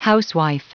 Prononciation du mot housewife en anglais (fichier audio)